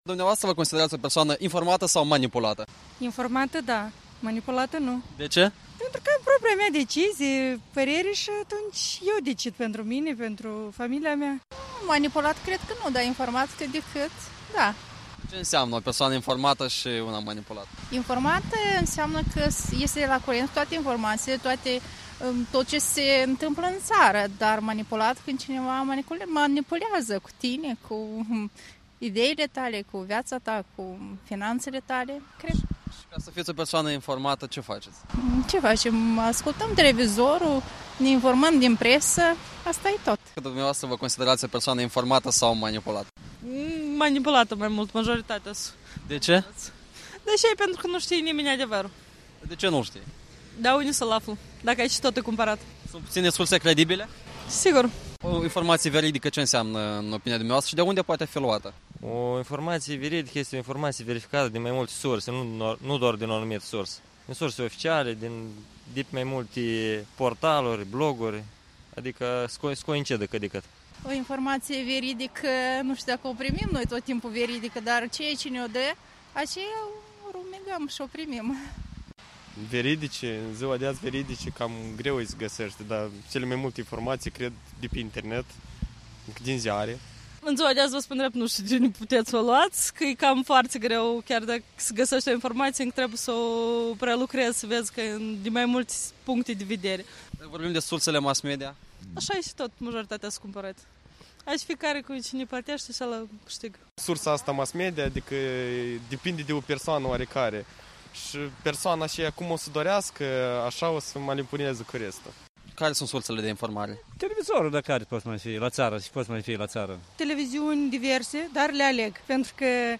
Vox populi pe străzile Chișinăului despre informarea și manipularea cetățenilor Republicii Moldova atunci când vorbim de sursele mass-media.
Vox populi despre informare și manipulare